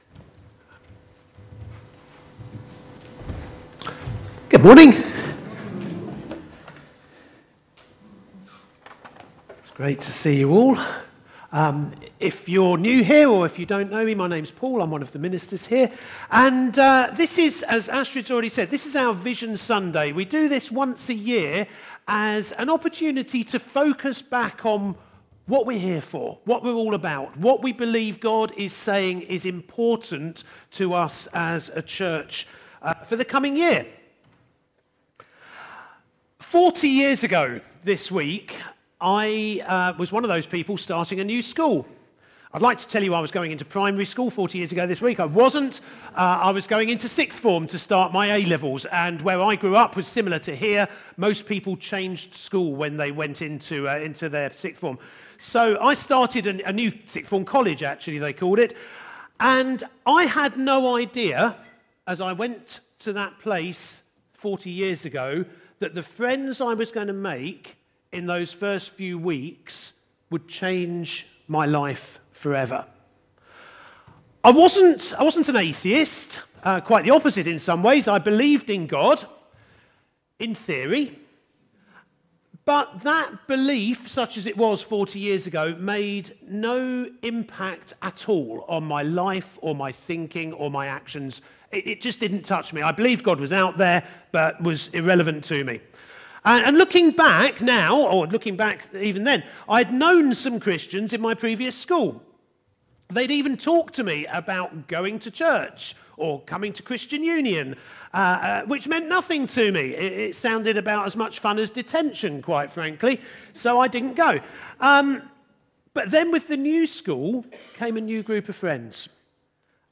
From Series: "Stand Alone Sermons 2025"